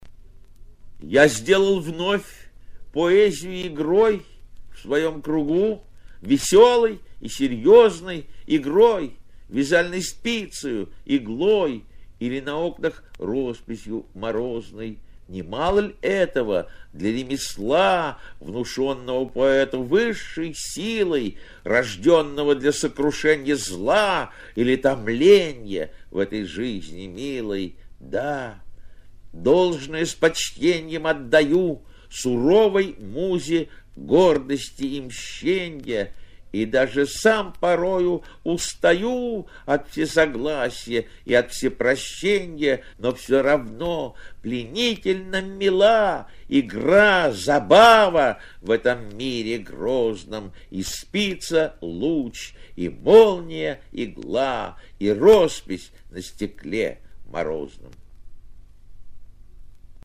david-samojlov-ya-sdelal-vnov-poeziyu-igroj-chitaet-avtor